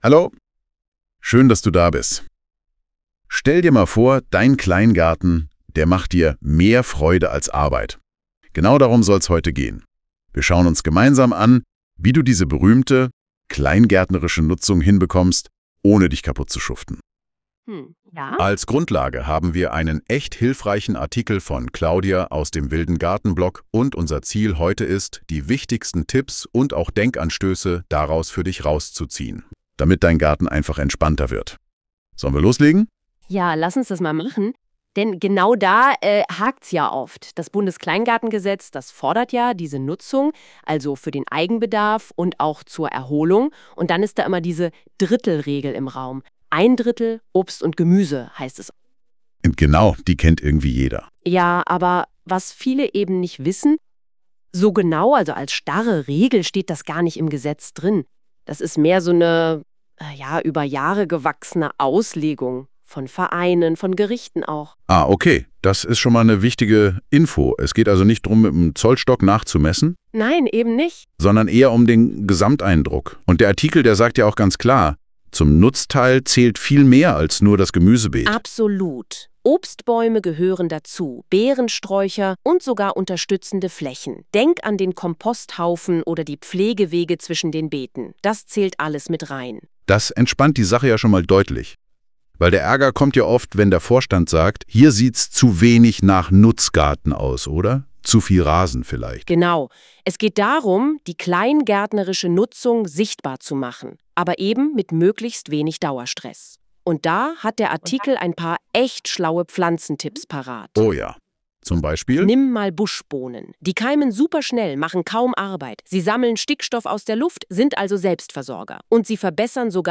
Ich hab mich über eine Stunde damit beschäftigt, Worte, Sätze und Pausen zu verändern, Schnipsel rauszuwerfen (3.Sprecher!) und seltsame „Doppelsprech“-Momente zu bereinigen, was nicht immer gelungen ist.
Spaßeshalbe setze ich das Ergebnis trotz der verbliebenen Mängel hier rein (gut zu hören ab Min 6.5) – für alle, die vielleicht auch mal damit spielen wollen. Die Betonungen sind stellenweise etwas daneben, die NotebookLM-Version klang flüssiger und natürlicher.